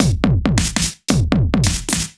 Index of /musicradar/off-the-grid-samples/110bpm
OTG_Kit 1_HeavySwing_110-B.wav